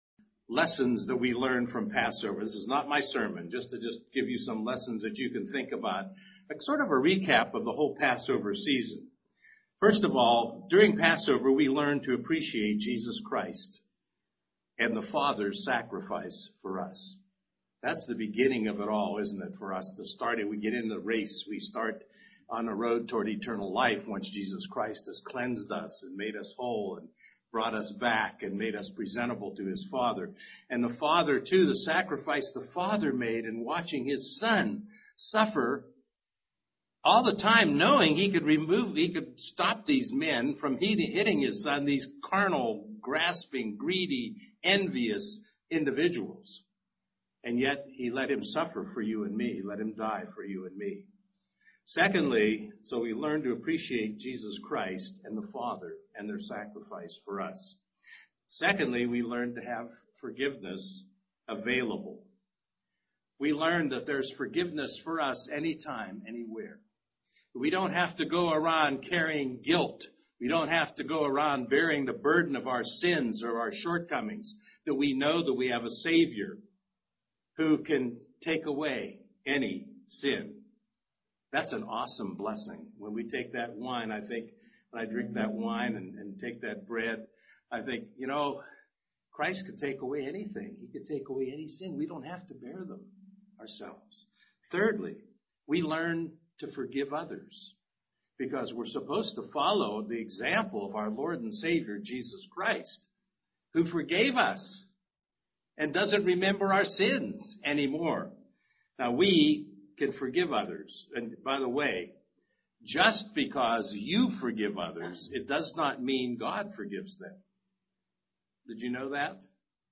Comparing our calling with the Exodus UCG Sermon Transcript This transcript was generated by AI and may contain errors.